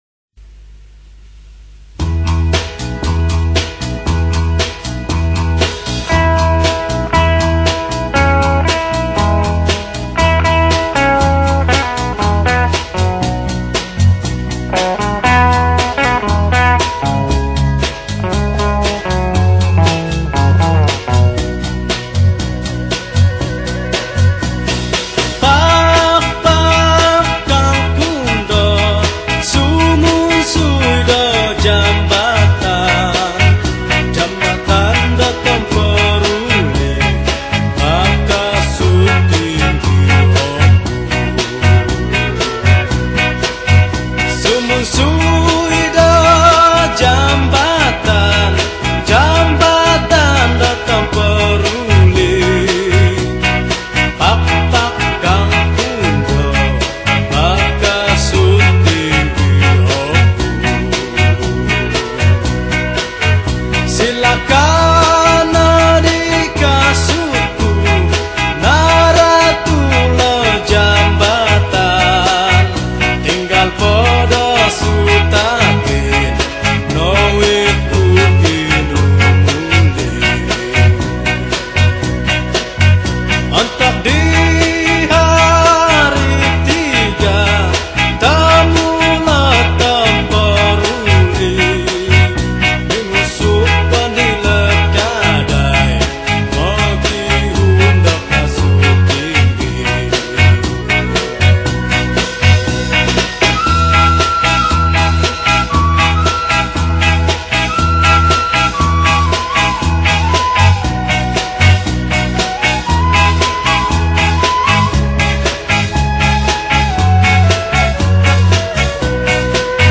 Kadazan Song